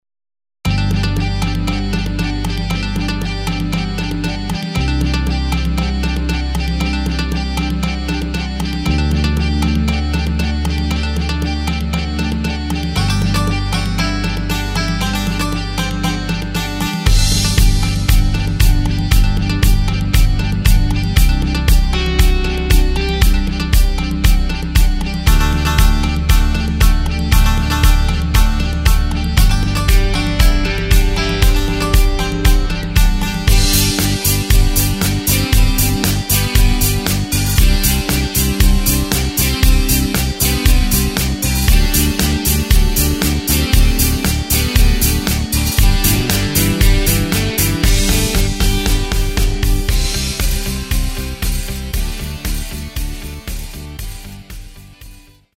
Takt:          4/4
Tempo:         117.00
Tonart:            G
Playback mp3 Demo